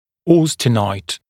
[ˈɔːstɪnaɪt][ˈо:стинайт]аустенит, аустенитный